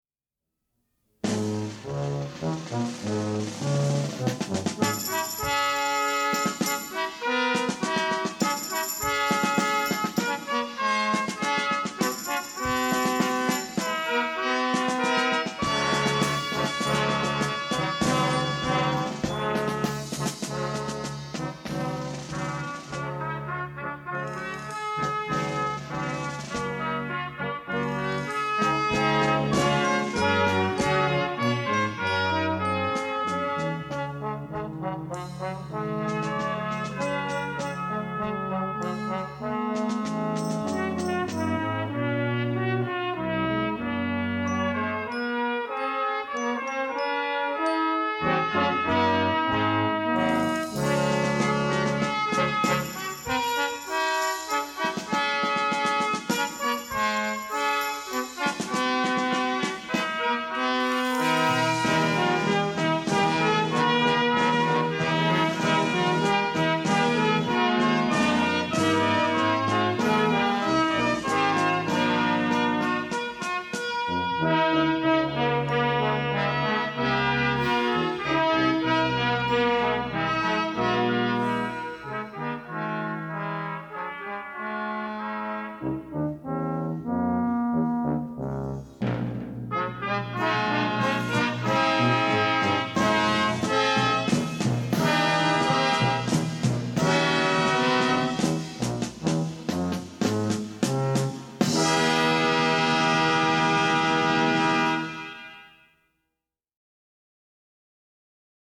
Beginning & Jr. Band Instrumentation
Jr. Band